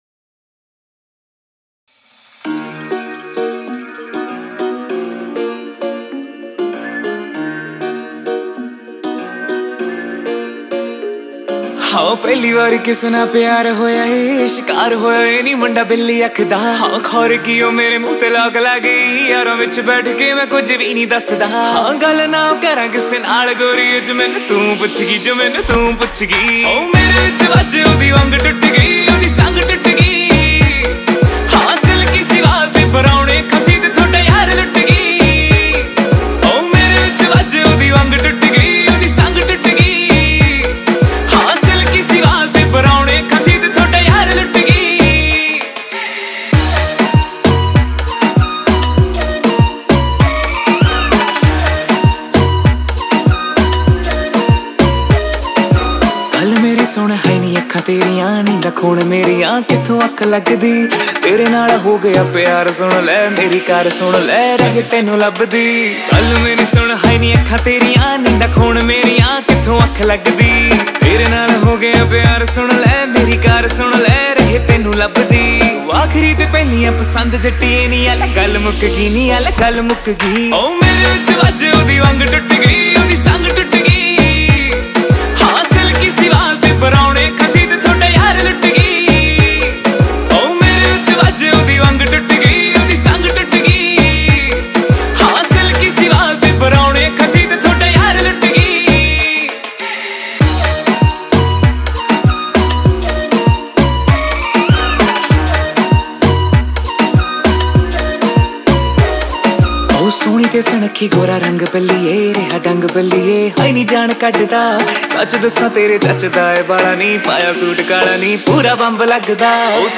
Category :Punjabi Music